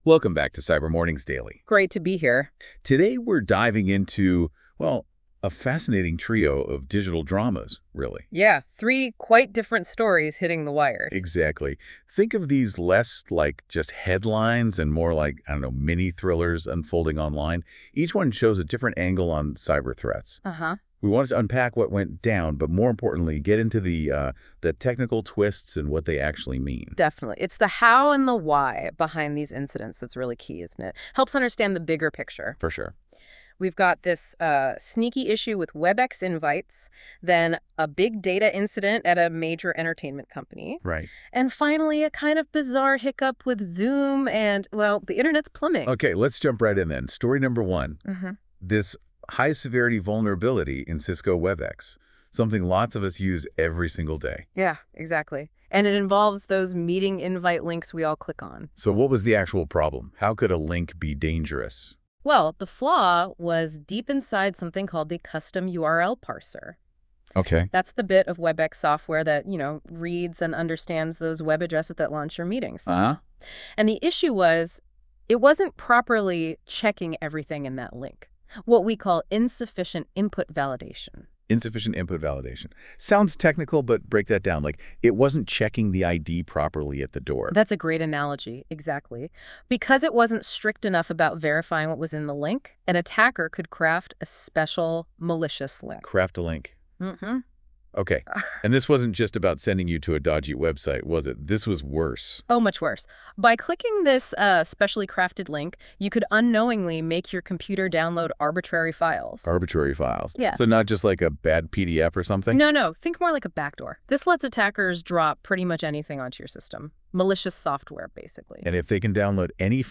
Cyber News by AI
Cyber Mornings Daily is your go-to daily podcast for the latest cybersecurity news, trends, and insights, delivered by AI. Each episode delivers a concise and informative breakdown of the most pressing cyber threats, vulnerabilities, and breaches.